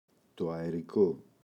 αερικό, το [aeri’ko] – ΔΠΗ